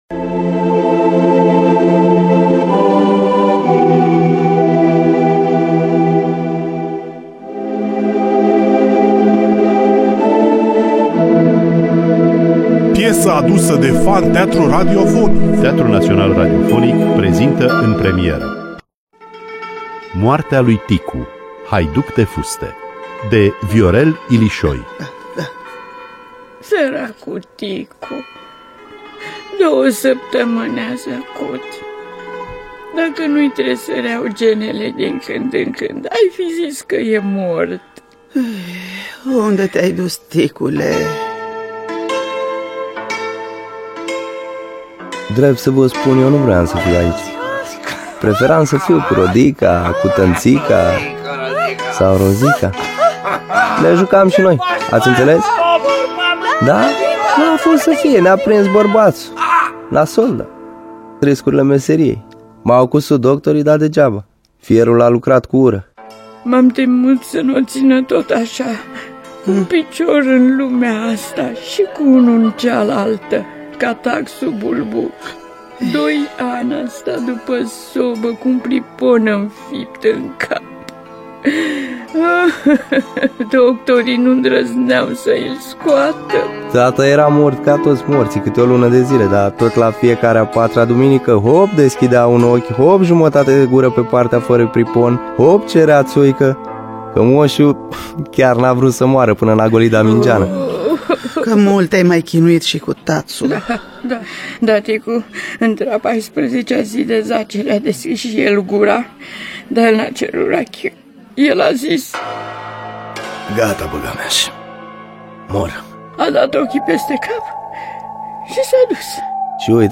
Dramatizarea radiofonică
Instrumentist ţambal